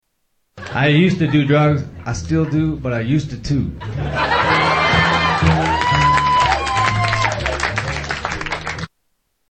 Category: Comedians   Right: Personal